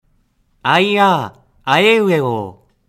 例えば「明日は、晴れるでしょう」という文章を母音のみで発音します。
滑舌母音練習１.mp3